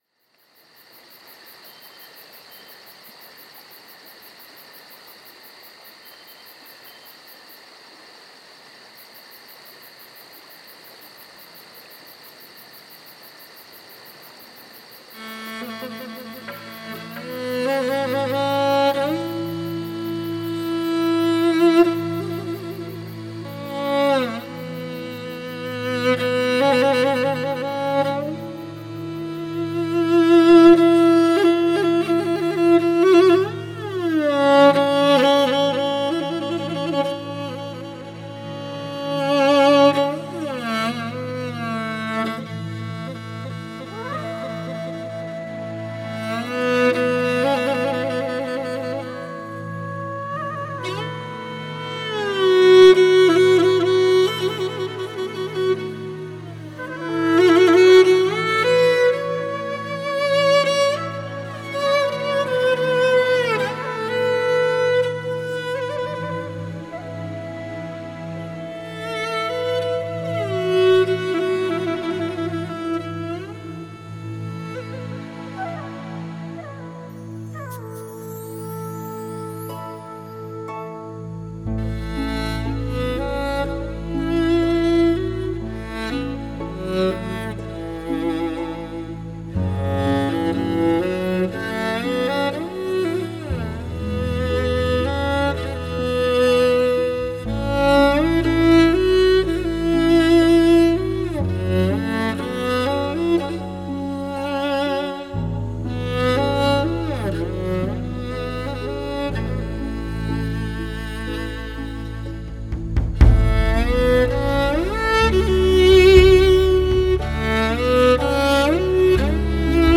2周前 纯音乐 9